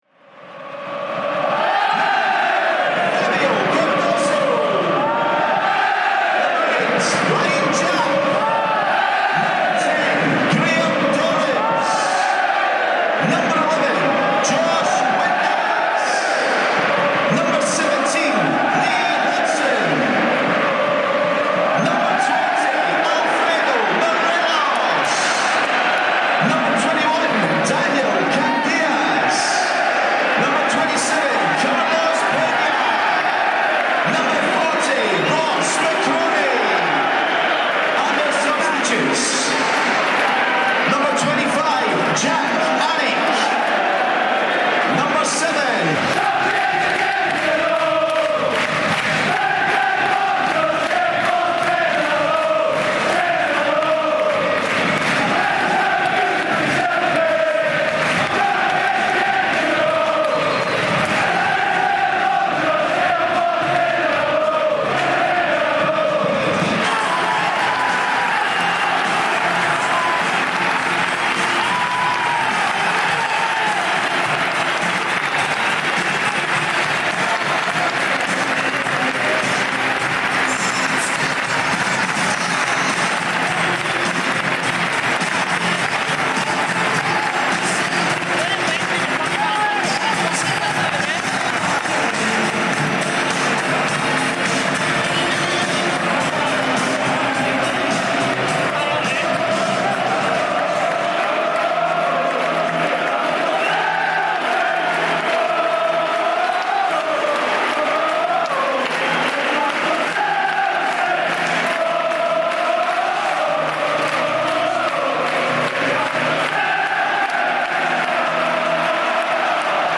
Audio was recorded at the game to try and capture the emotion of the day
so excuse the audio as the podcast is recorded in the car on the way back